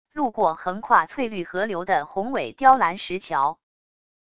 路过横跨翠绿河流的宏伟雕栏石桥wav音频